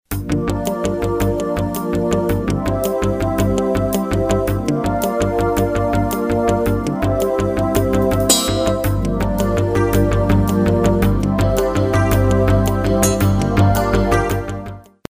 Documentary 70b